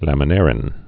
(lămə-nârĭn)